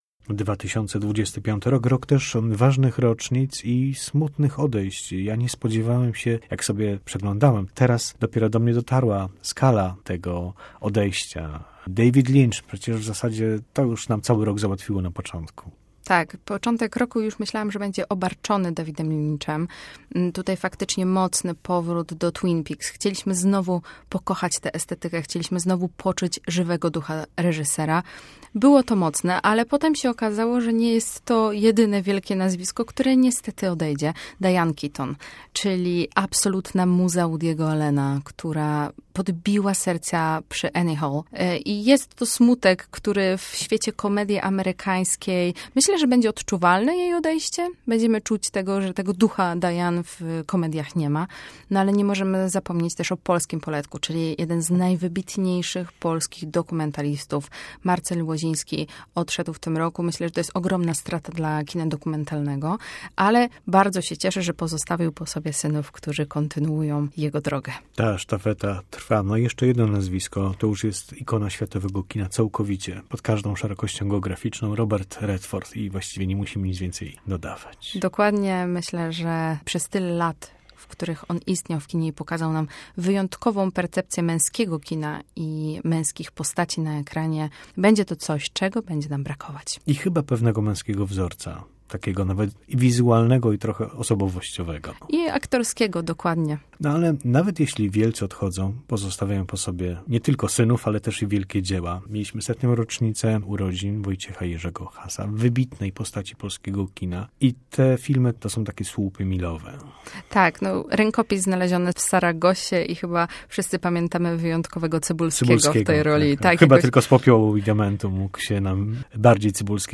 Ale to nie jedyna wielka postać filmu, która odeszła w 2025 roku. O innych wielkich nieobecnych w rozmowie